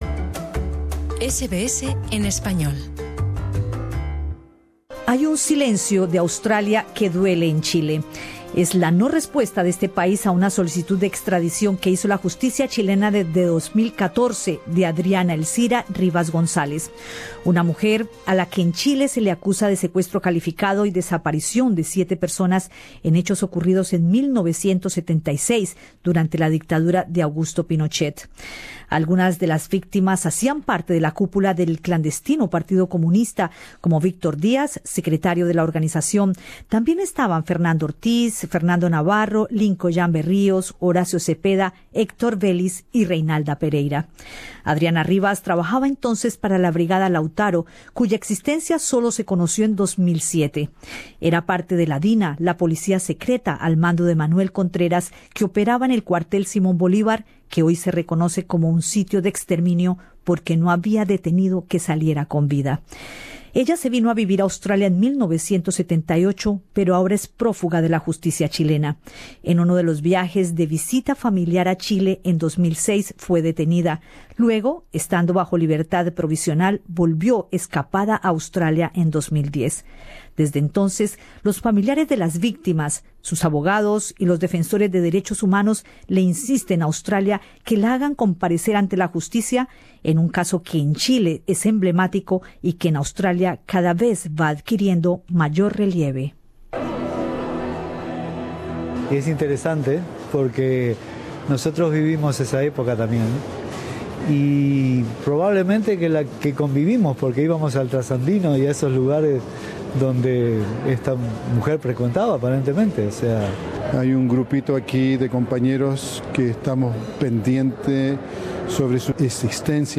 Aquí te lo contamos en un informe especial.-Escucha el podcast, de la parte superior.